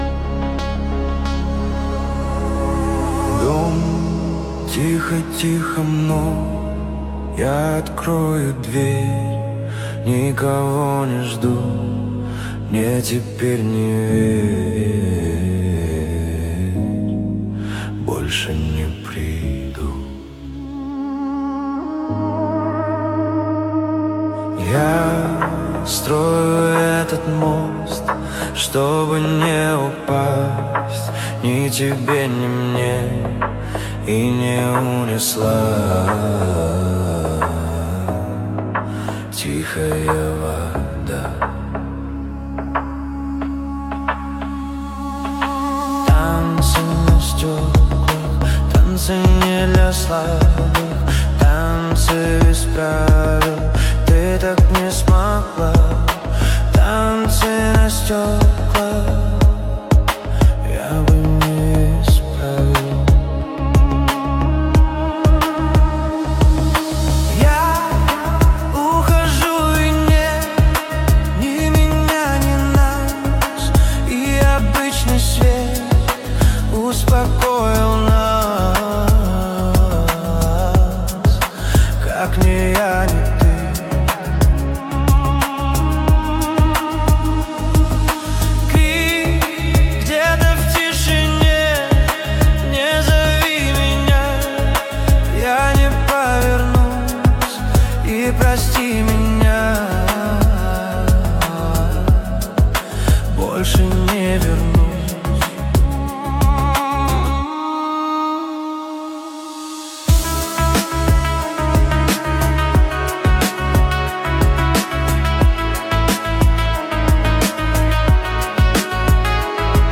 AI трек